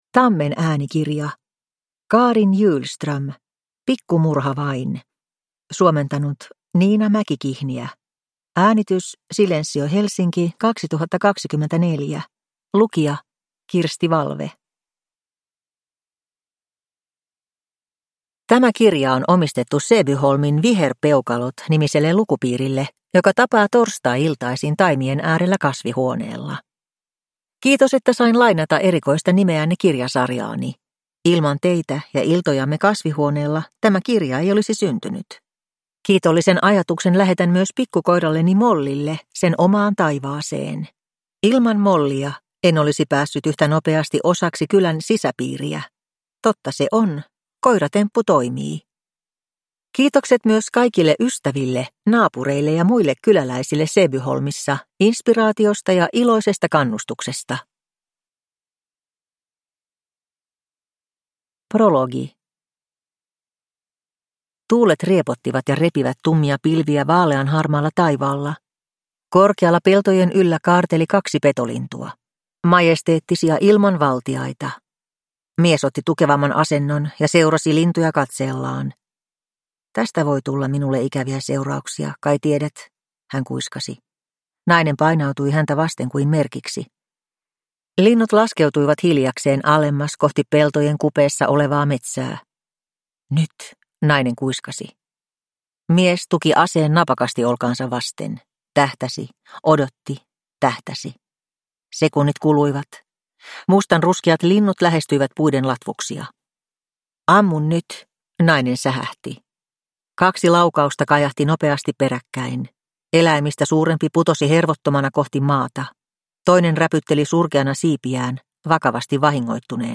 Pikku murha vain (ljudbok) av Carin Hjulström